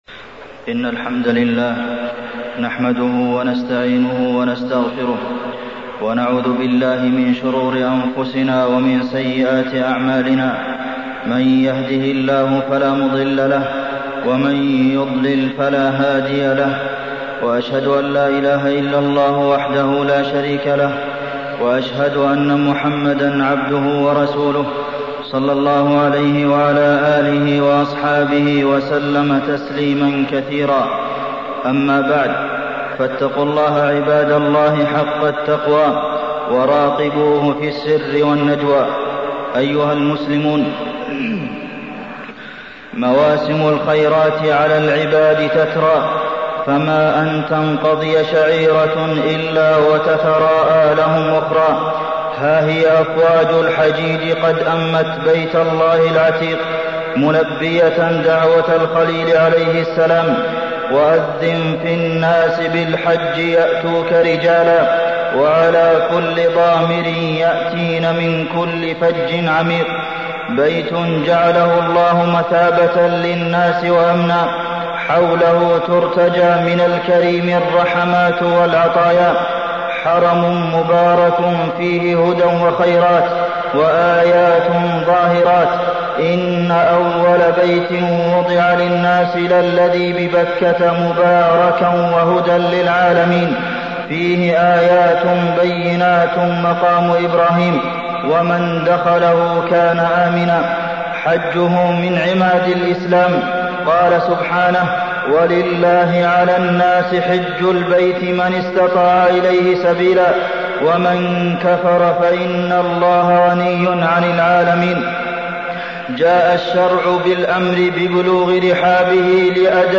تاريخ النشر ٣ ذو الحجة ١٤٢٥ هـ المكان: المسجد النبوي الشيخ: فضيلة الشيخ د. عبدالمحسن بن محمد القاسم فضيلة الشيخ د. عبدالمحسن بن محمد القاسم الحج